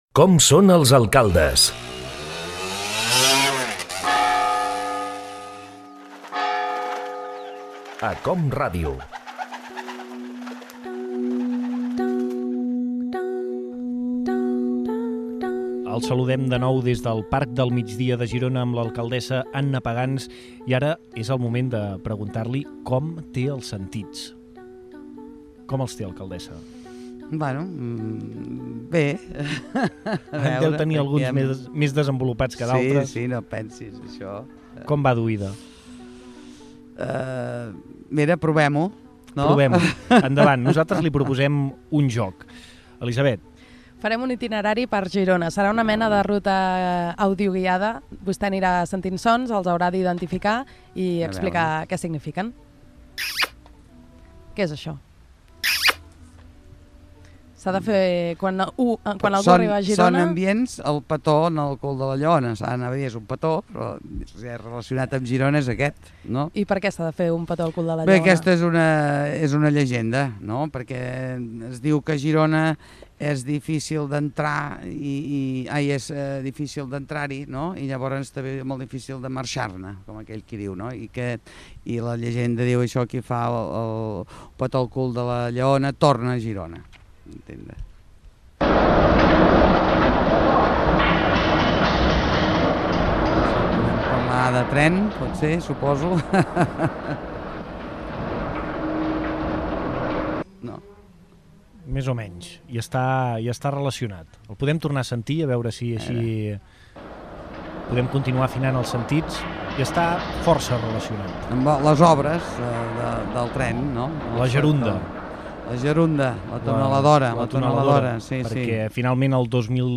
Fragment d'una entrevista a l'alcaldesa de Girona, Anna Pagans. Comença amb diferents sons de la ciutat que s'han d'identificar.
Divulgació